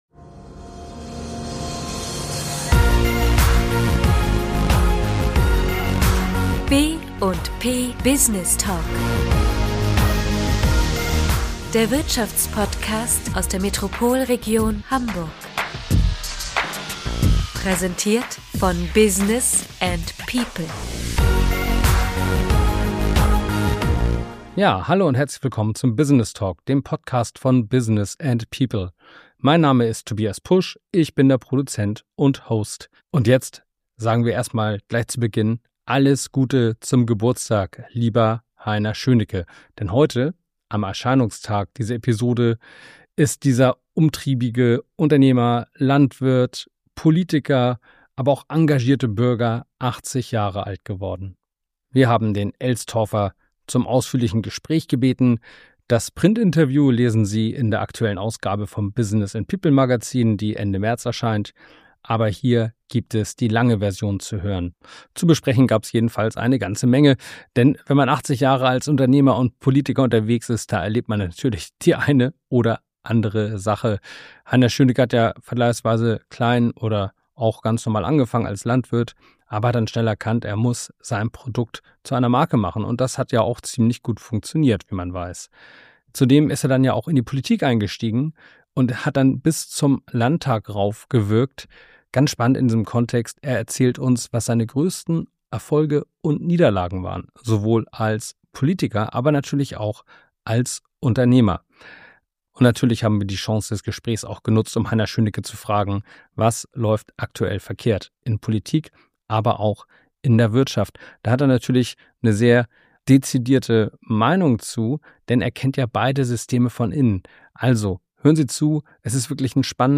Heute, am 21. März 2026, feiert der rastlose Elstorfer seinen 80. Geburtstag. Im Gespräch mit Business & People blickt er auf sein Wirken zurück und redet über aktuelle Fehlentwicklungen in Wirtschaft und Politik.